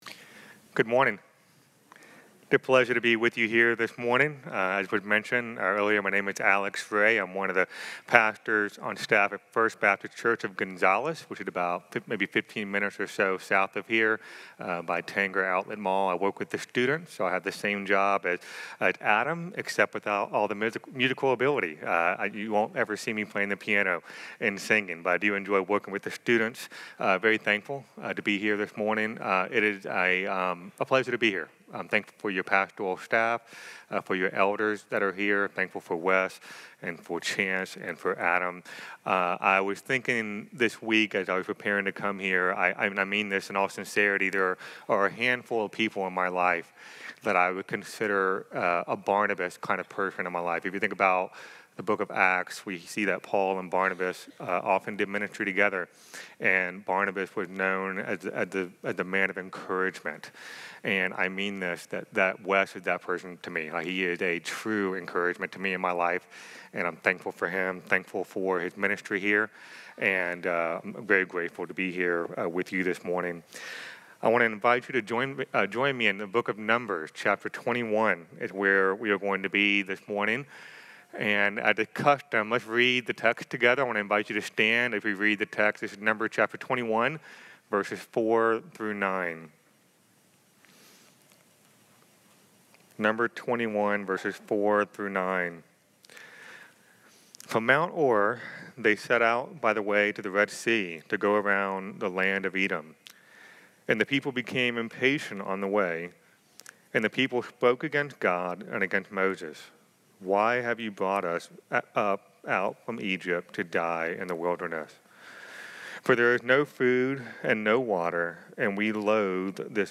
25.12.28-Sermon.mp3